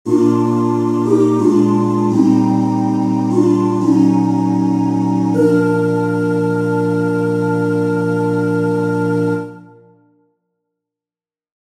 How many parts: 4
Type: Barbershop
All Parts mix: